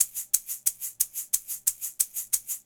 Shaker 11 Eggs Over Easy.wav